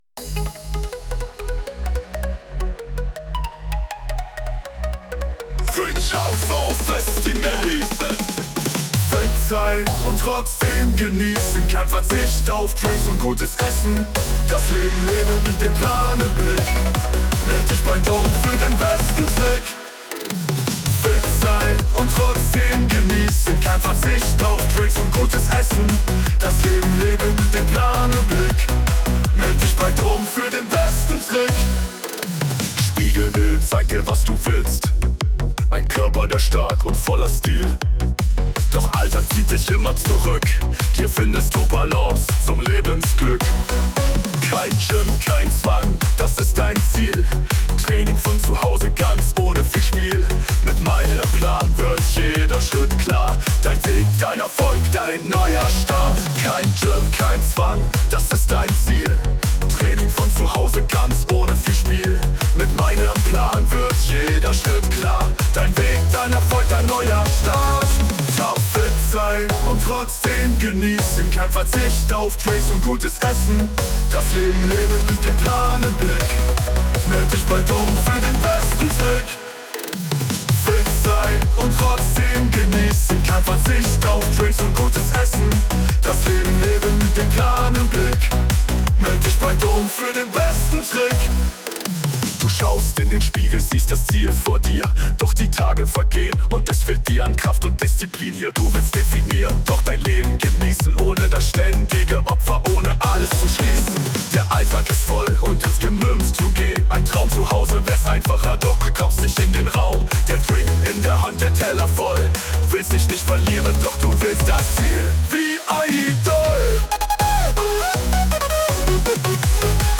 Kostenlose Lieder mit motivierenden Texten (und geilem Beat ;‒)